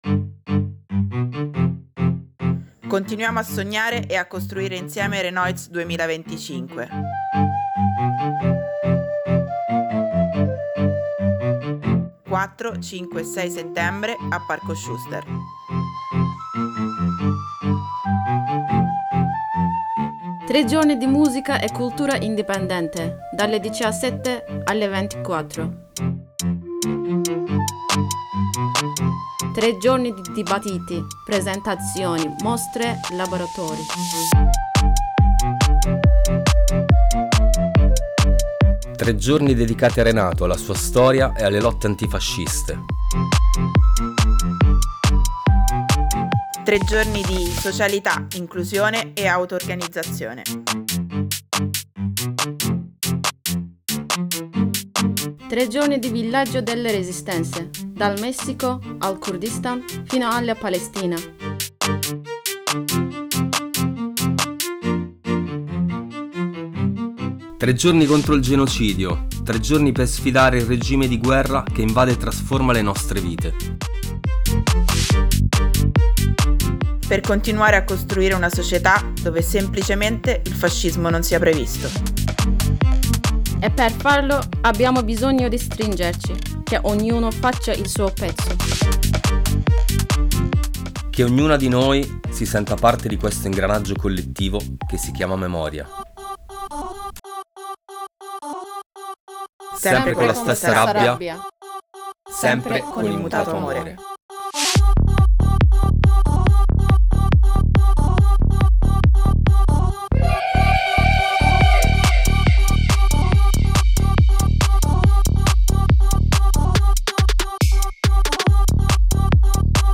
spot renoize